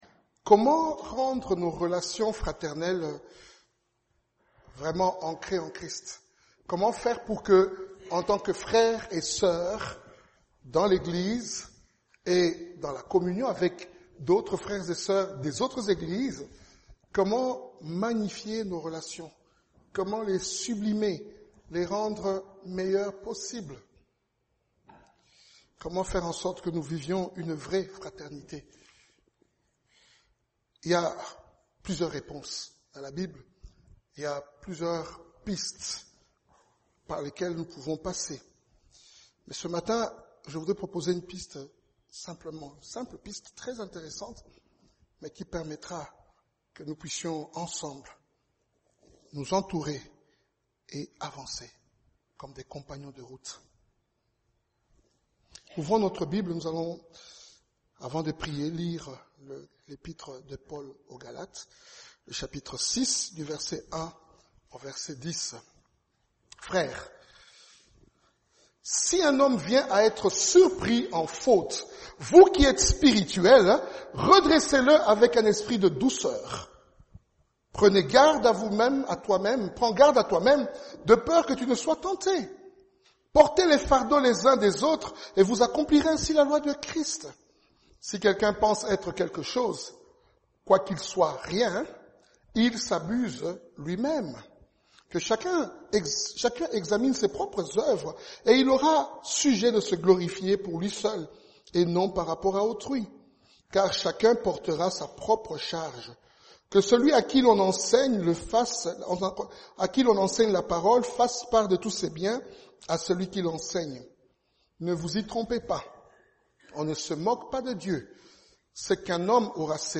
Passage: Galates 6 : 1-10 Type De Service: Dimanche matin